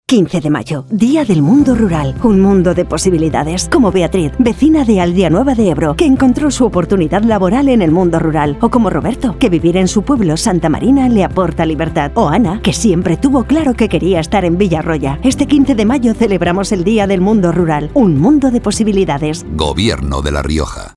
Cuñas radiofónicas